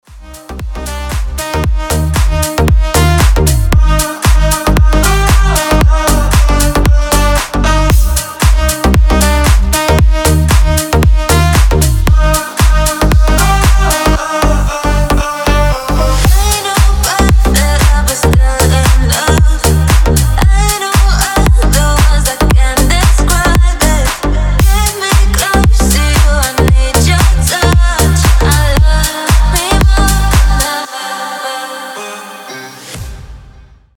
• Качество: 320, Stereo
deep house
dance
Красивый deep в нарезке